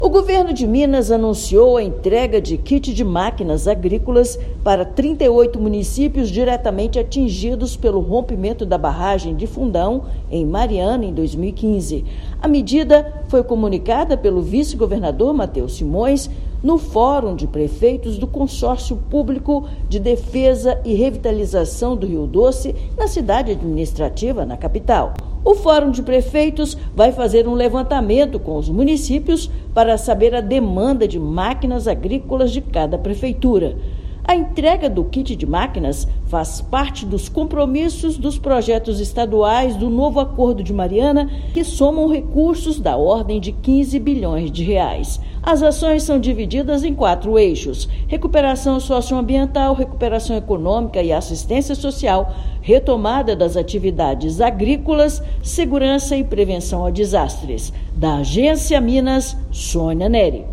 RADIO_ENTREGA_DE_MAQUINAS_AGRICOLAS.mp3